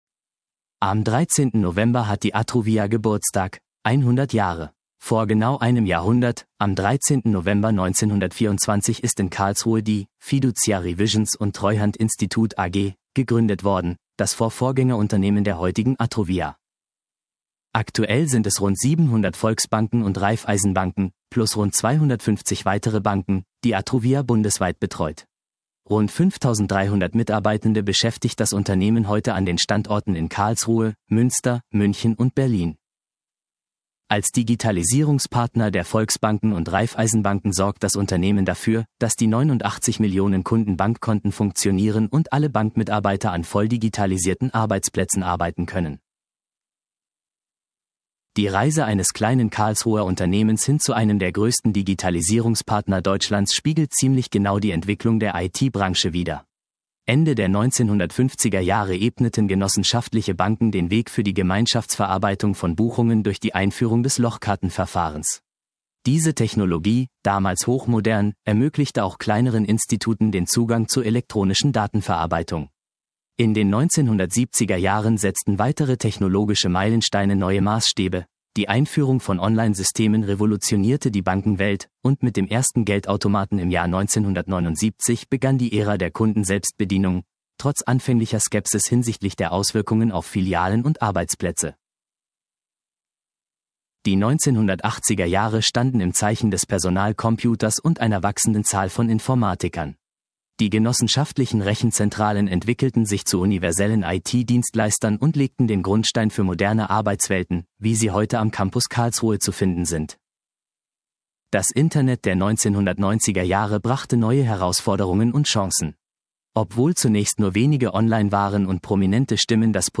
Ein raumfüllender Riesenschrank: Die Tabelliermaschine IBM 421 im Betrieb.